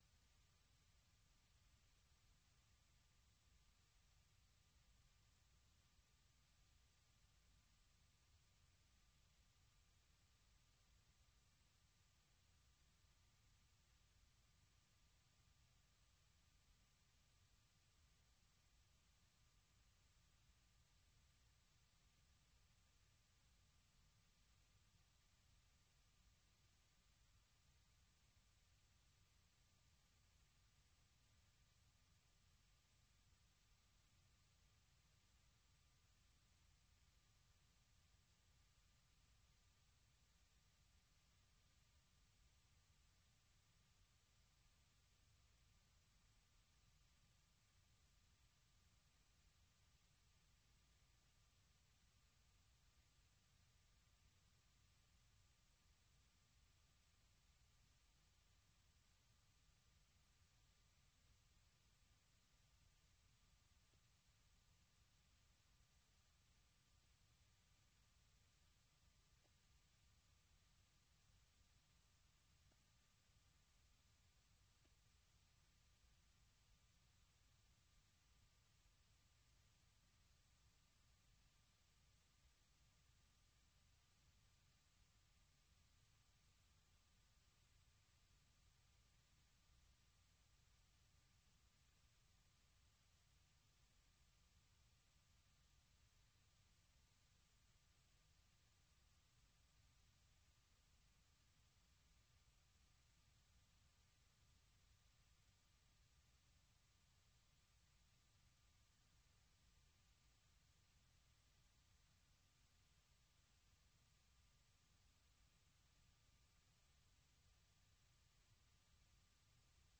Le programme quotidien d'appel de VOA Afrique offre aux auditeurs un forum pour commenter et discuter d'un sujet donné, qu'il s'agisse d'actualités ou de grands sujets de débat.